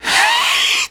SOFT ATTAC.wav